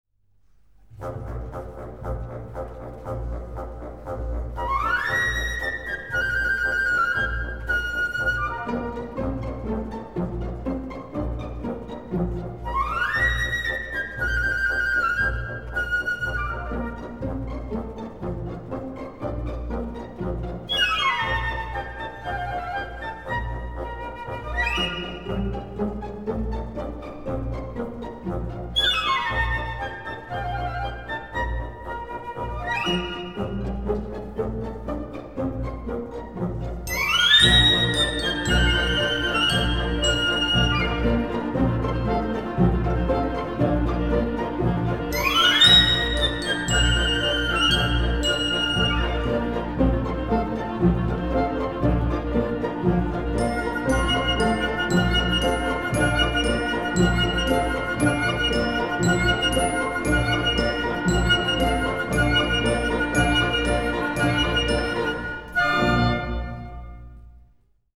Ballet
Orquesta
Música clásica